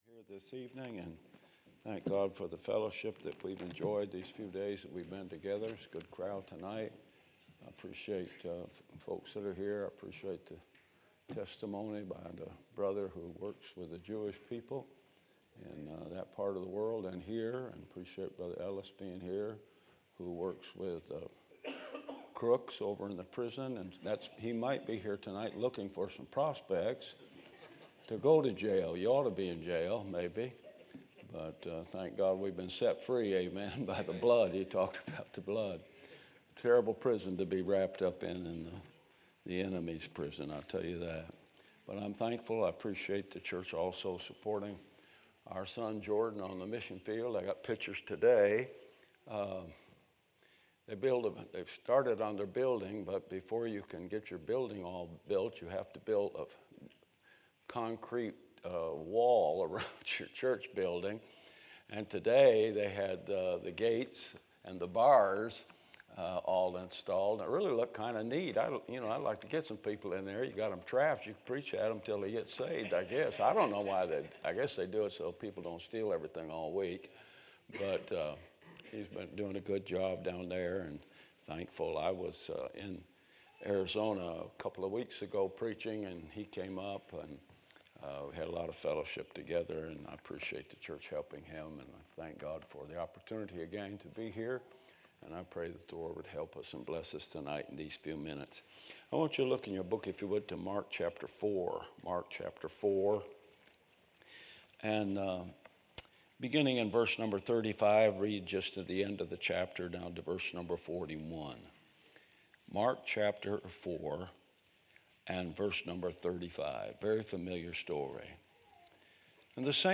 Sermons - Bible Baptist Church